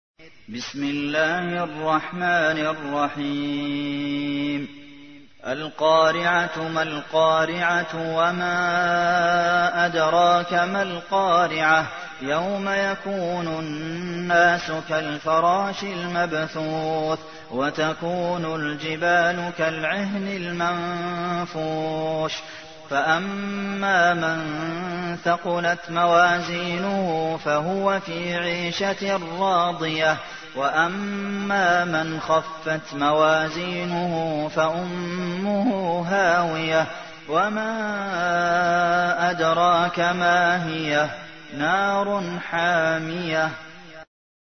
تحميل : 101. سورة القارعة / القارئ عبد المحسن قاسم / القرآن الكريم / موقع يا حسين